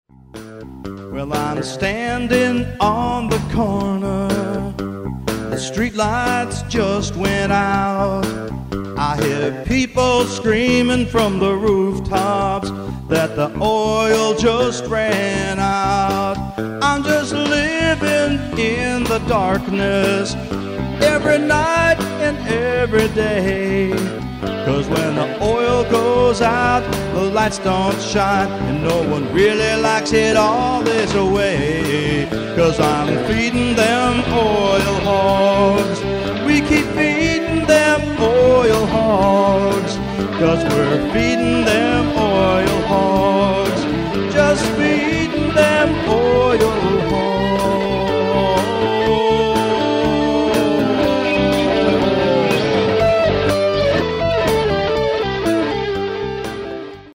Original Music - Rock & Blues
Here are short samples of some of my original rock tunes.